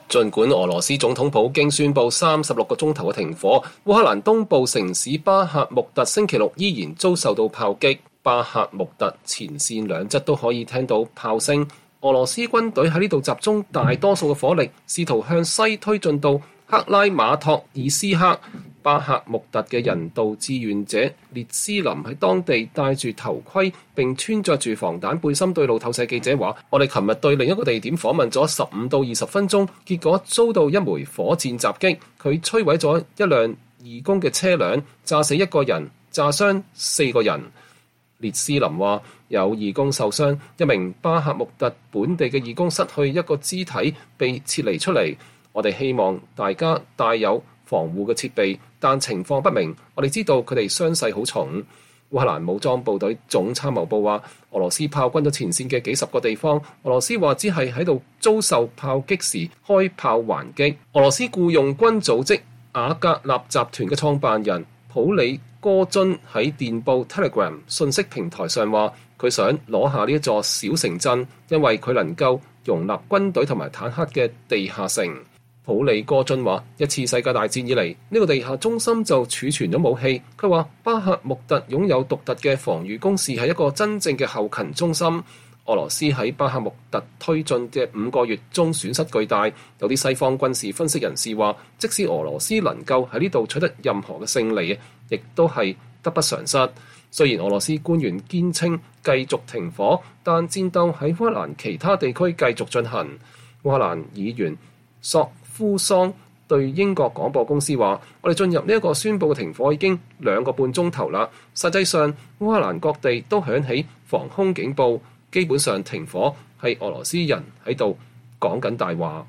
烏克蘭東部城鎮巴赫穆特在東正教聖誕節遭受俄羅斯轟炸（2023年1月7日）
儘管俄羅斯總統普京宣佈36小時的停火，烏克蘭東部城市巴赫穆特星期六依然遭受炮轟。巴赫穆特（Bakhmut）前線兩側都可以聽到炮聲，俄羅斯軍隊在這裡集中了大多數火力，試圖向西推進到克拉馬托爾斯克（Kramatorsk）。